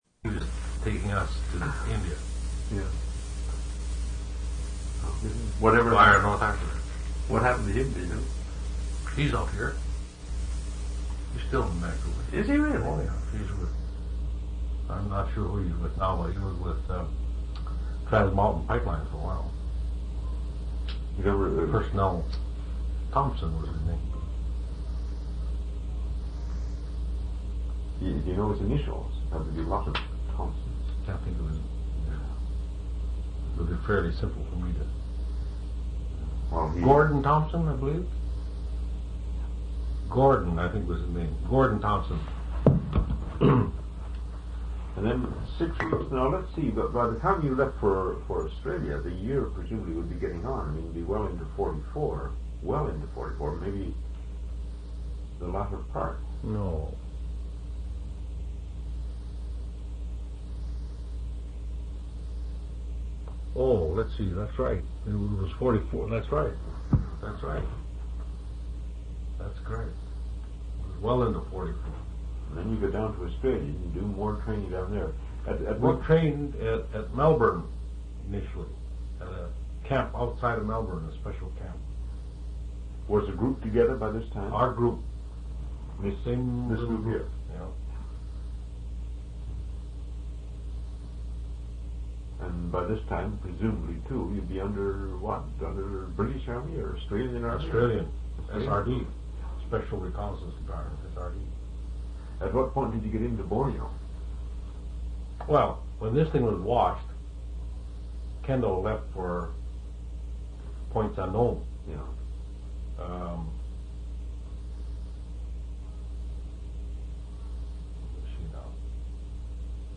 interviews
One original sound tape reel (ca. 75 min.) : 1 7/8 ips, 2 track, mono.
oral histories